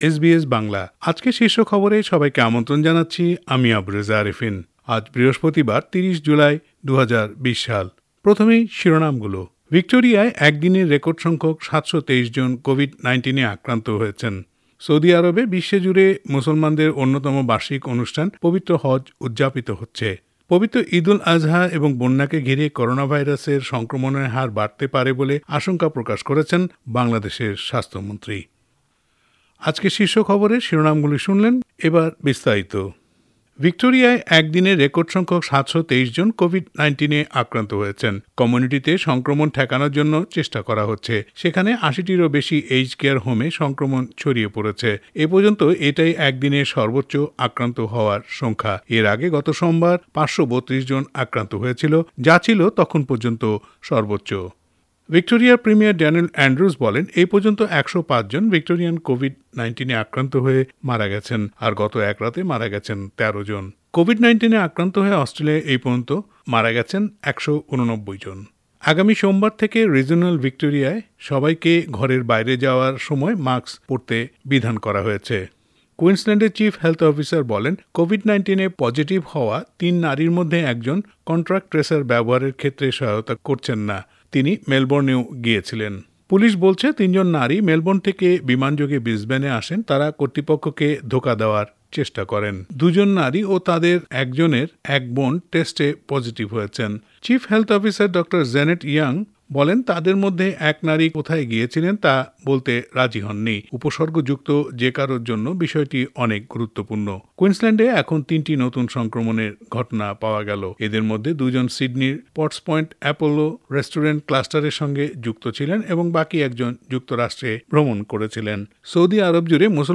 এস বি এস বাংলা শীর্ষ খবর :৩০ জুলাই ২০২০